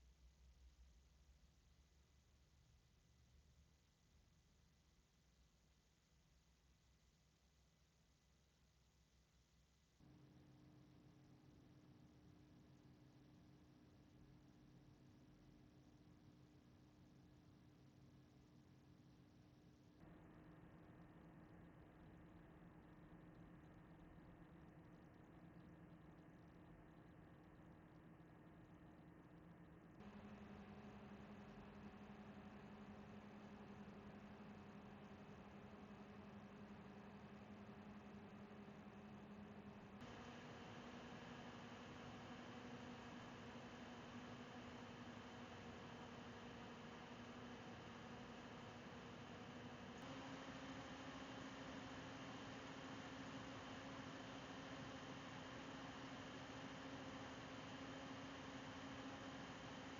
So hört sich der Kühler an
• 00:00 – 00:10: < 30 dB(A), min. U/min
Der Turris 620 zeichnet sich durch eine hohe Laufruhe aus: Bei einer festgelegten Drehzahl bleibt die Geräuschcharakteristik unverändert.
Dabei stechen beim Turris 620 früh die Motoren der Lüfter aus dem Betriebsgeräusch heraus, während das Luftrauschen weniger stark ausgeprägt ist.
TRYX Turris 620: min. Drehzahl (340 U/min) über 31, 35, 40, 45 bis 48 dB(A) (max. 1.700 U/min)